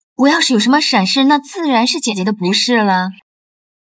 Professional Korean Voiceover Services | Natural AI Narration
AI Synthesis